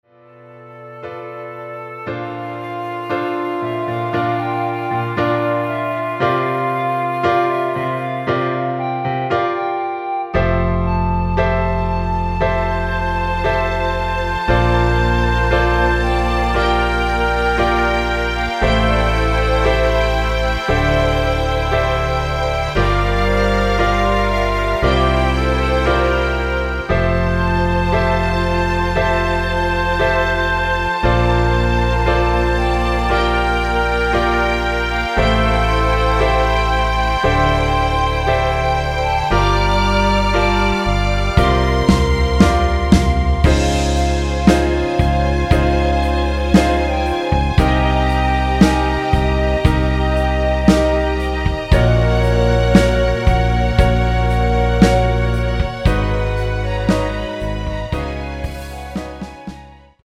2절 부분은 삭제하고 어둠이 찾아 들어로 연결 됩니다.(가사및 미리듣기 참조)
원키에서(+5)올린 (2절 삭제) 멜로디 포함된 MR입니다.
앞부분30초, 뒷부분30초씩 편집해서 올려 드리고 있습니다.
(멜로디 MR)은 가이드 멜로디가 포함된 MR 입니다.